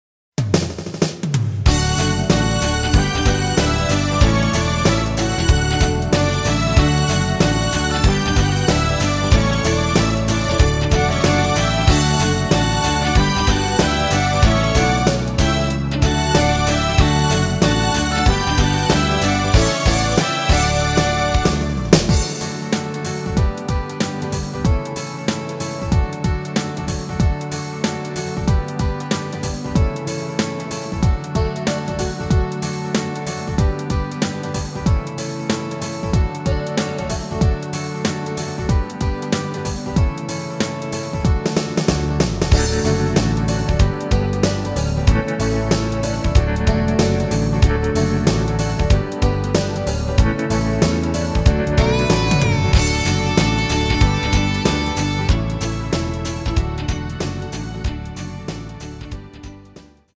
대부분의 남성분이 부르실수 있는 키로 제작 하였습니다.